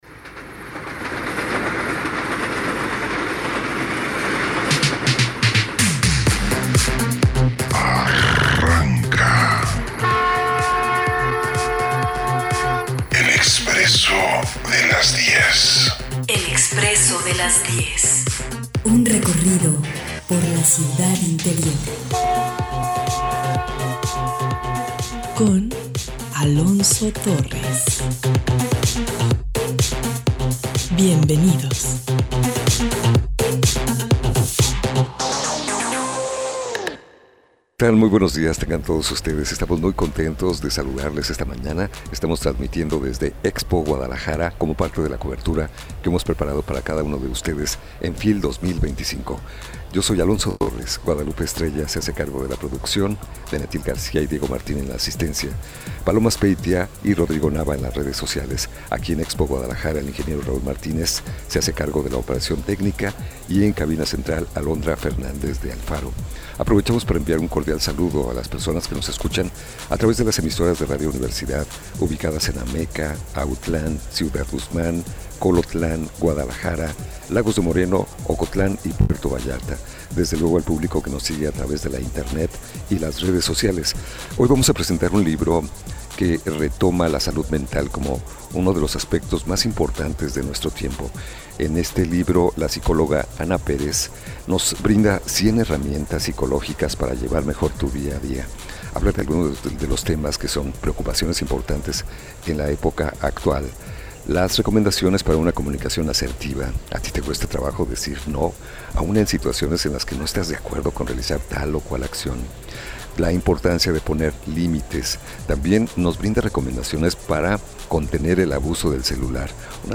Como parte de nuestra cobertura en la feria internacional del libro de Guadalajara